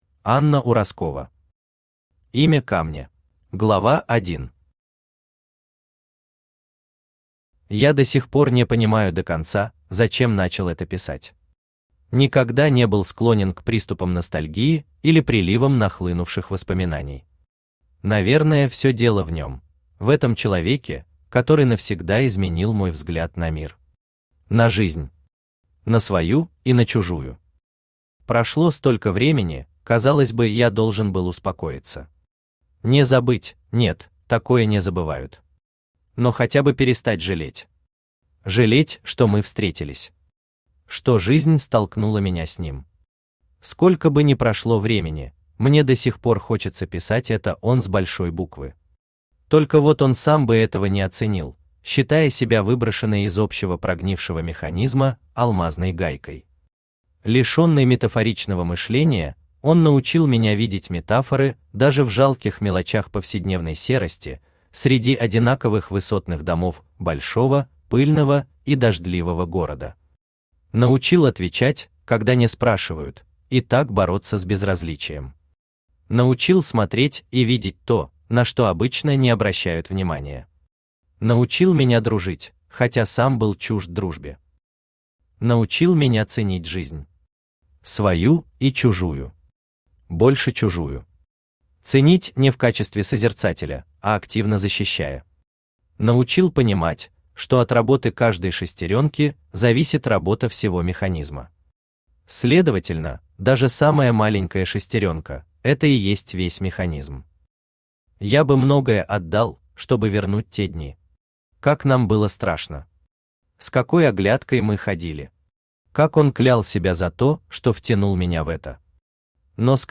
Аудиокнига Имя камня | Библиотека аудиокниг
Читает аудиокнигу Авточтец ЛитРес